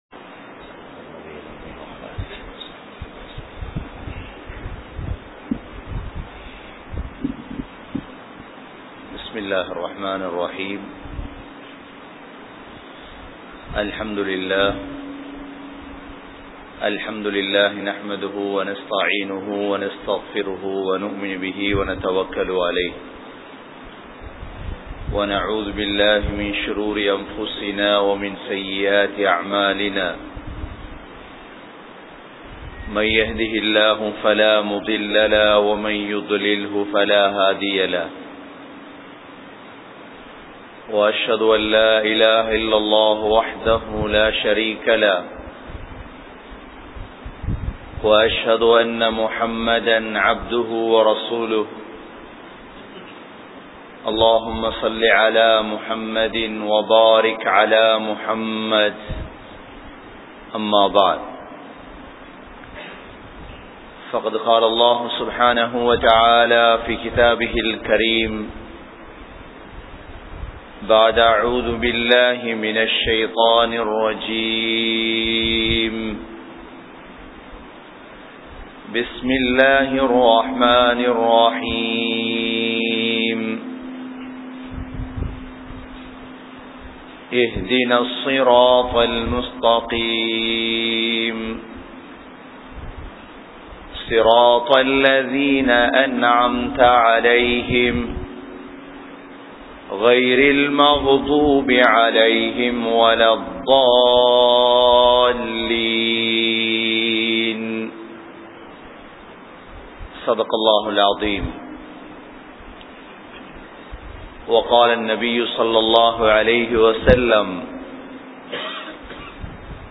Palastine Unmaiel Muslimkalin Naadaa? | Audio Bayans | All Ceylon Muslim Youth Community | Addalaichenai
Galle, Kanampittya Masjithun Noor Jumua Masjith